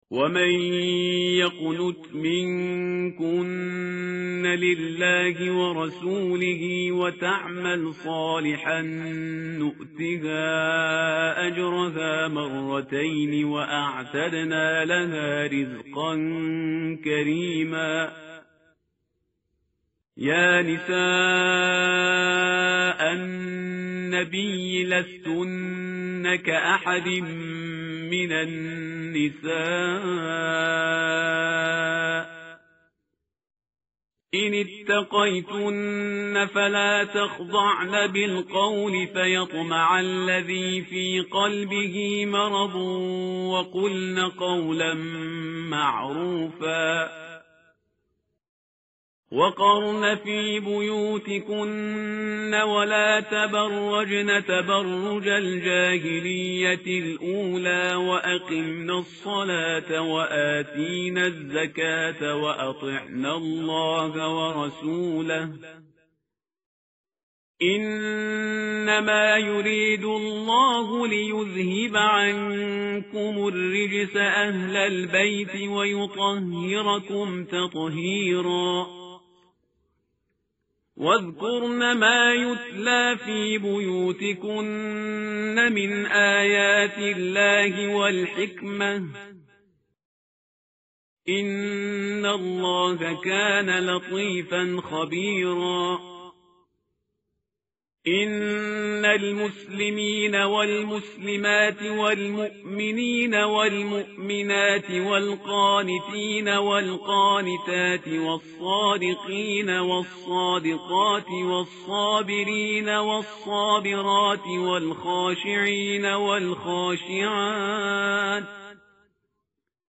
متن قرآن همراه باتلاوت قرآن و ترجمه
tartil_parhizgar_page_422.mp3